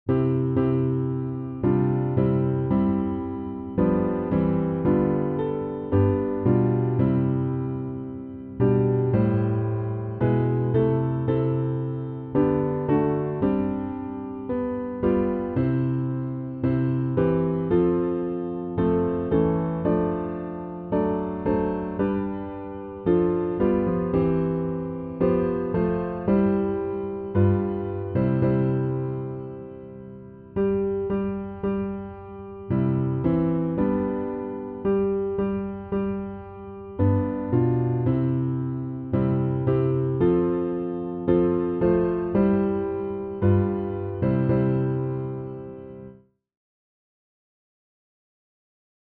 It is Well with My Soul (reharmonized)
Voicing/Instrumentation: SATB , Organ/Organ Accompaniment , Piano Prelude/Postlude , SATB quartet We also have other 39 arrangements of " It is Well with My Soul ".